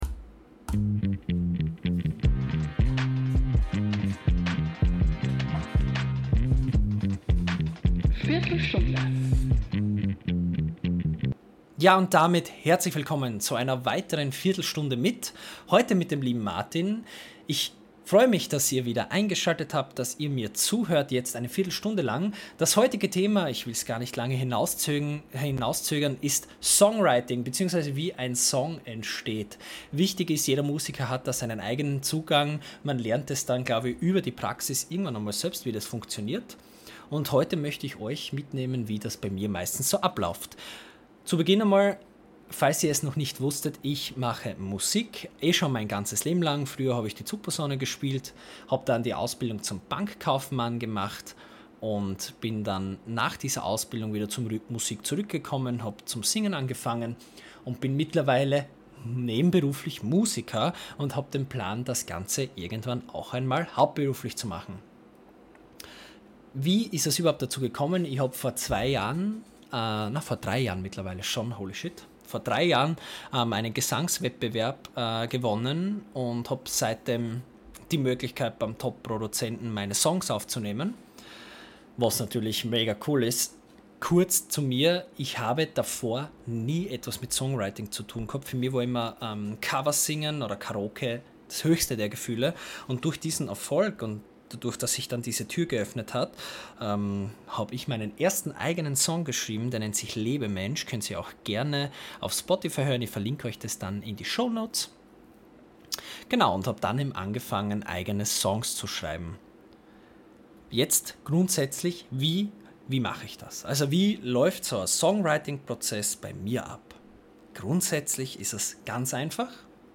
In dieser Folge rede ich einfach frei drauflos über mein Songwriting. Ganz ohne festen Plan, eher so wie meine Songs auch entstehen.